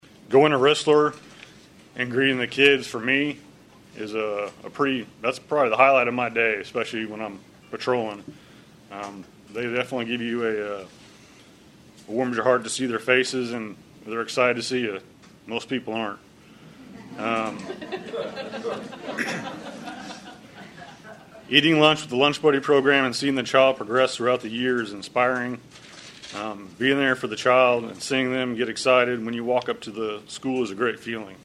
Bright Futures Trenton Advisory Board members and Trenton R-9 school counselors spoke at the Bright Futures Trenton Volunteer Recognition and Celebration Luncheon on October 27.